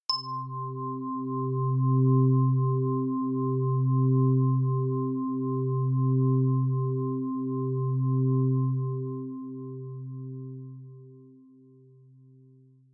AmbientChime
ambient bell chime ding ring sound effect free sound royalty free Nature